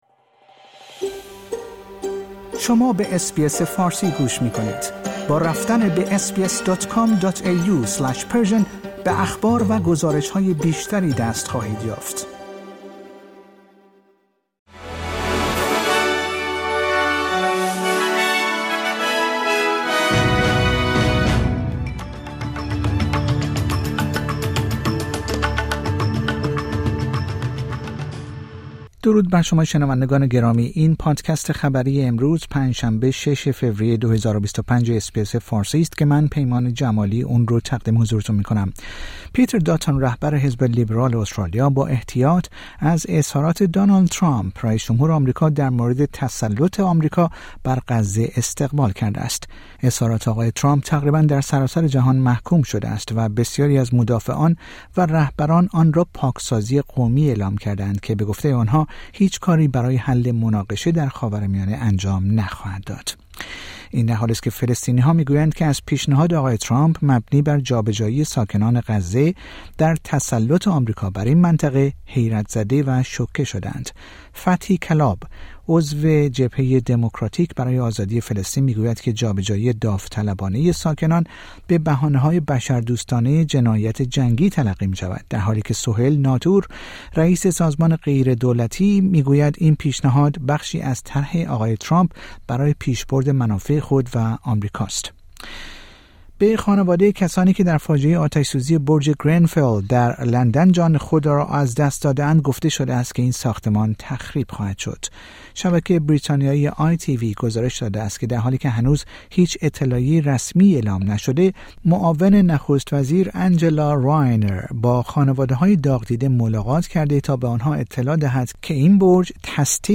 در این پادکست خبری مهمترین اخبار استرالیا در روز پنج شنبه ۶ فوریه ۲۰۲۵ ارائه شده است.